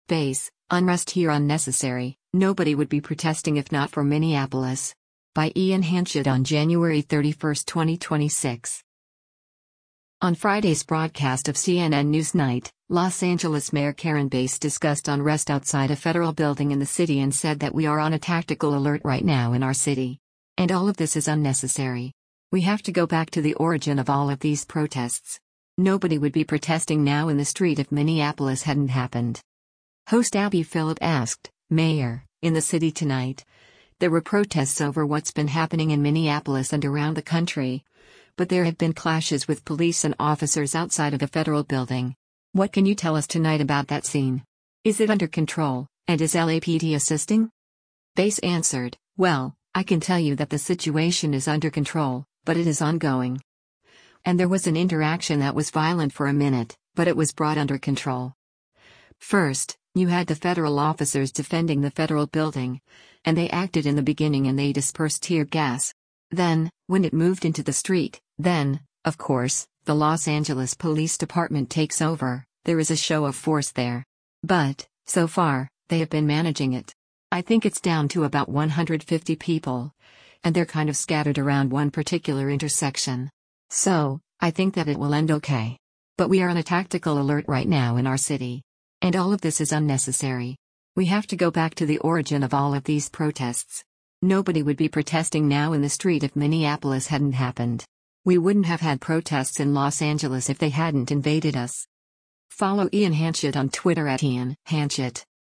On Friday’s broadcast of “CNN NewsNight,” Los Angeles Mayor Karen Bass discussed unrest outside a federal building in the city and said that “we are on a tactical alert right now in our city.